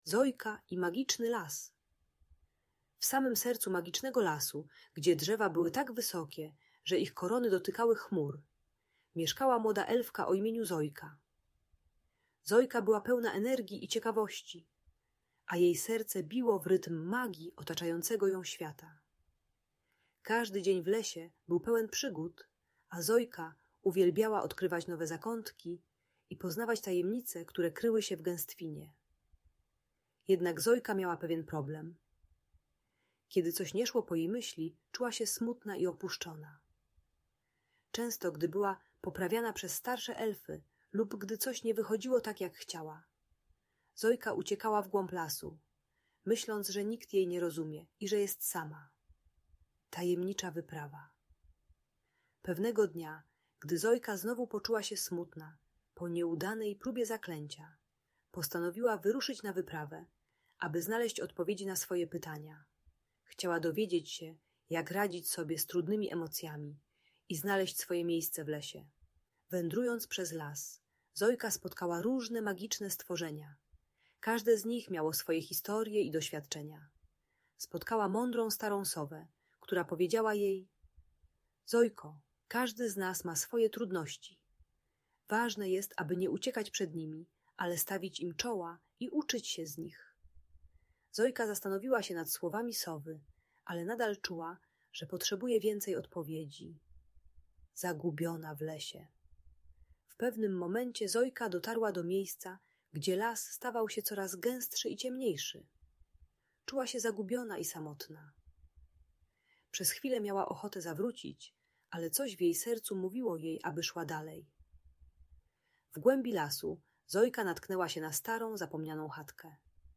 Zojka i Magiczny Las - Przygody elfki w magicznej story - Audiobajka